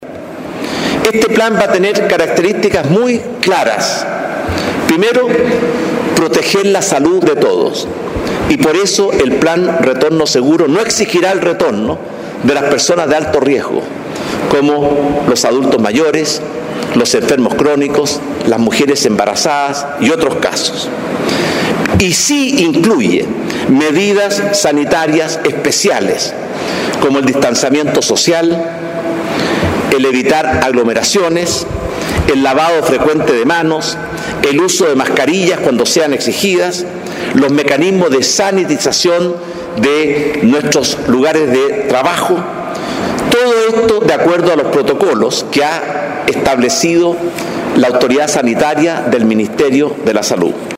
En un punto de prensa en La Moneda, el mandatario indicó que existirán tres fases, donde los empleados públicos serán los primeros en volver, para luego los privados. Por último, los estudiantes en su vuelta a clases será la fase final.